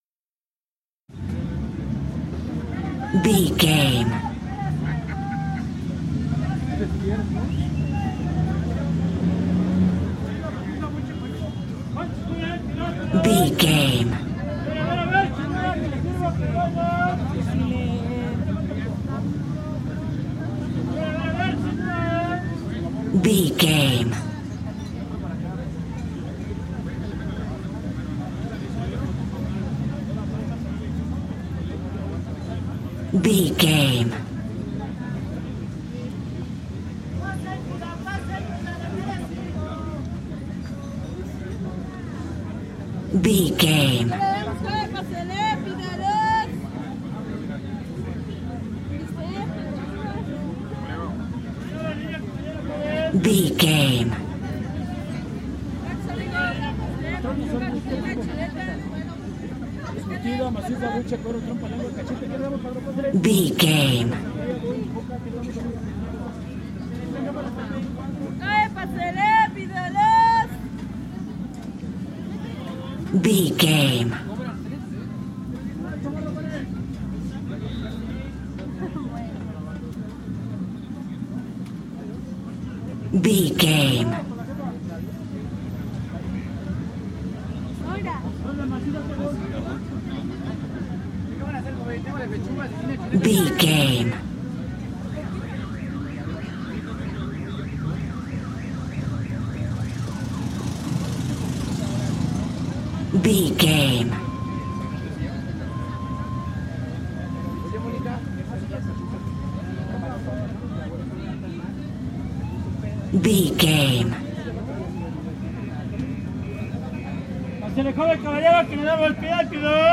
Spanish street market 34
Sound Effects
urban
chaotic
ambience